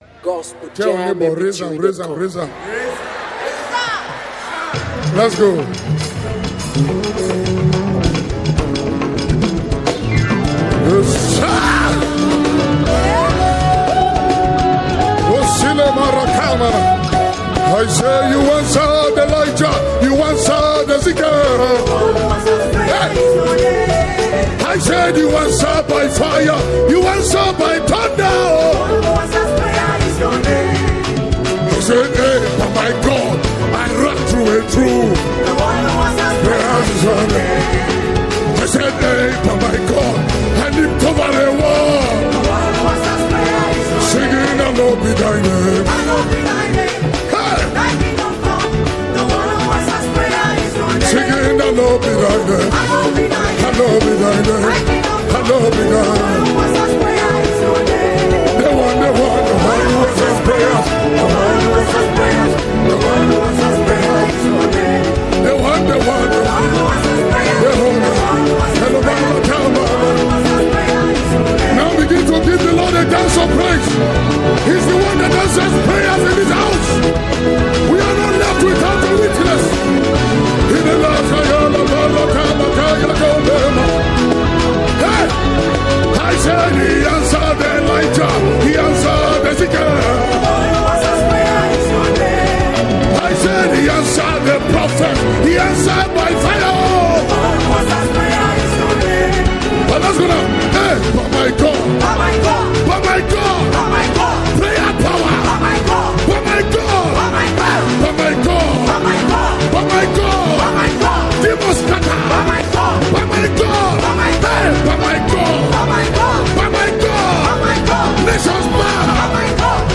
medley